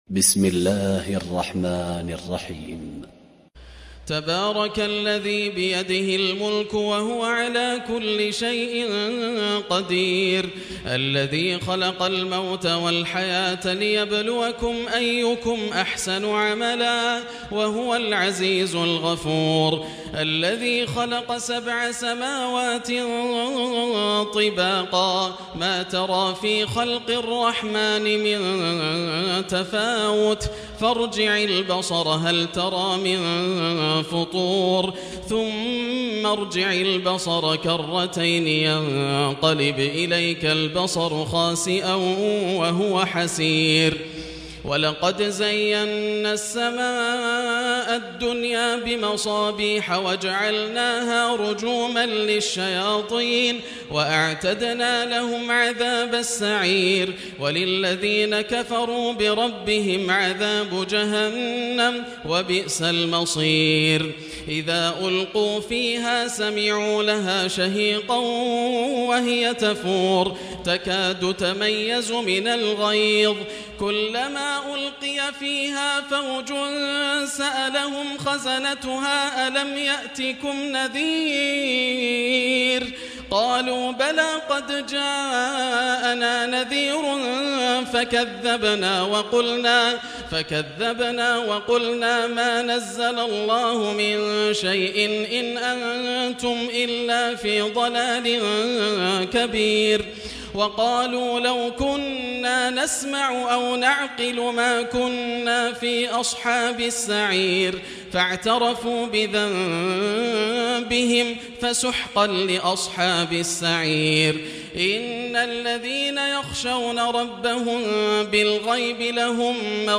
سورة الملك من تراويح رمضان 1440هـ > السور المكتملة > رمضان 1440هـ > التراويح - تلاوات ياسر الدوسري